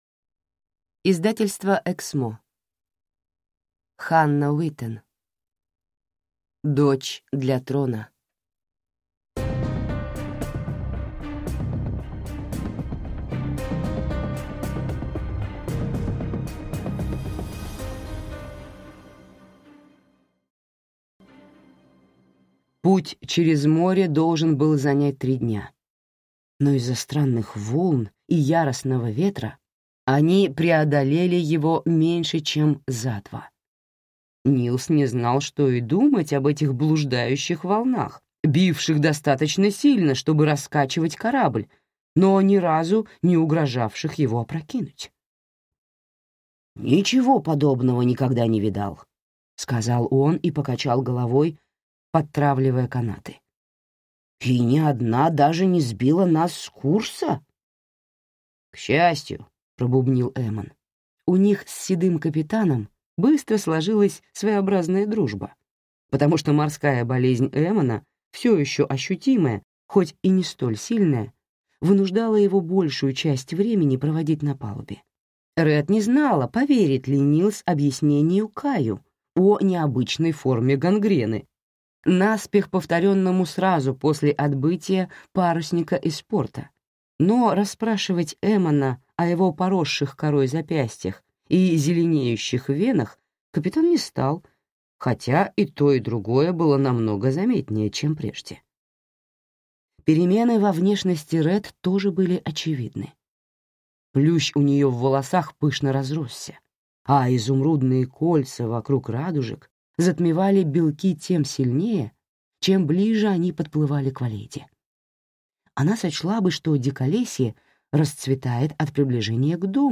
Аудиокнига Дочь для трона | Библиотека аудиокниг